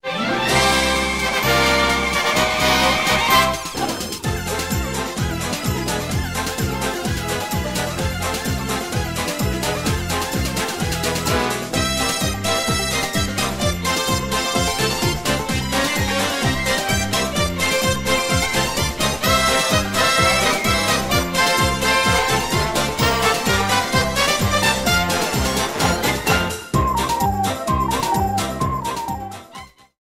Trimmed, normalized and added fade-out.